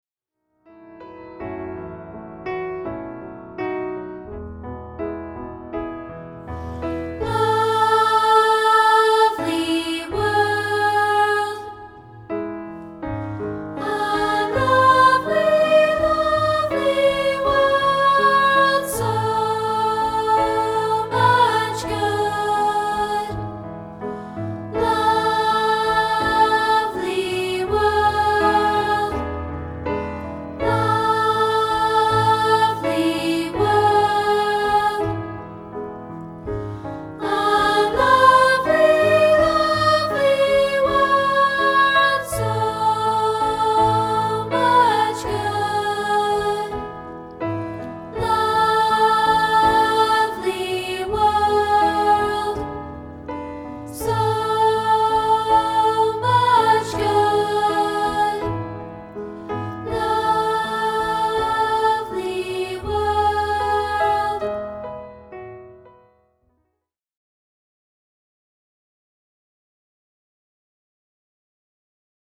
rehearsal tracks